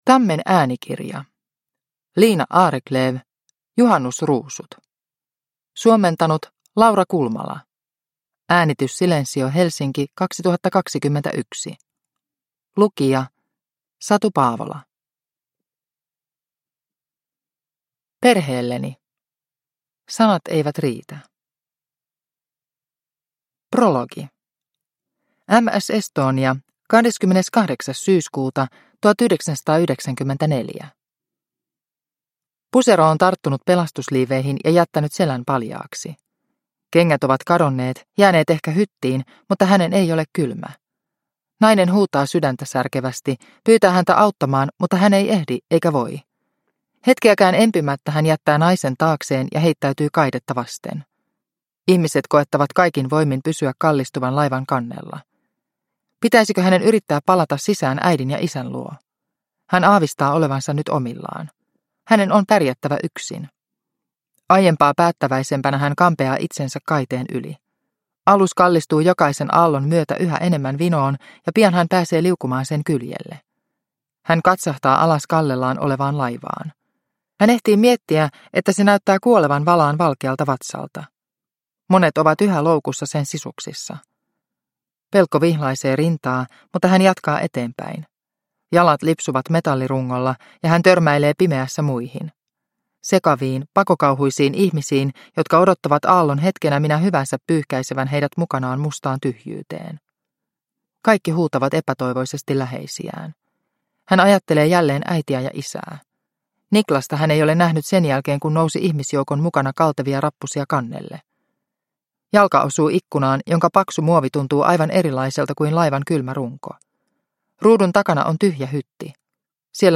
Juhannusruusut – Ljudbok – Laddas ner